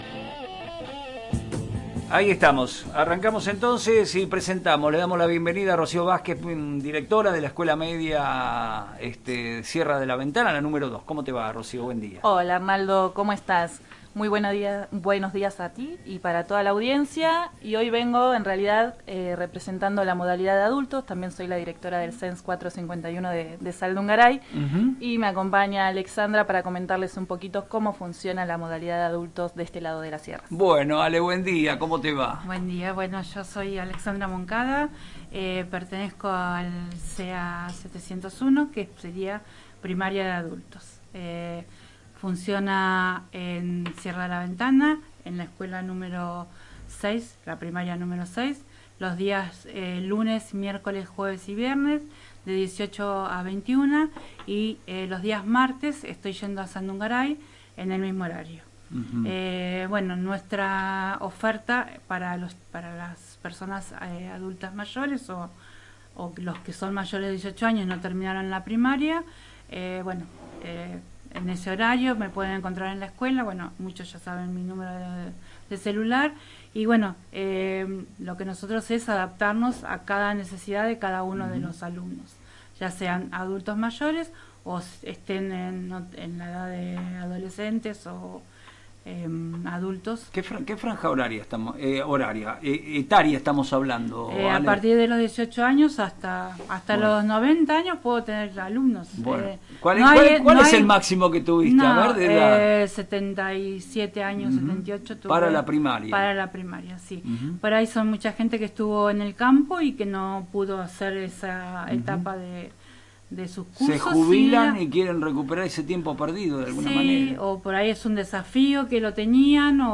visitaron los estudios de Radio Reflejos para detallar las propuestas pedagógicas en Sierra de la Ventana, Saldungaray y Tornquist. Con inscripciones abiertas y un sistema de enseñanza flexible, buscan que los mayores de 18 años cierren etapas y proyecten su futuro.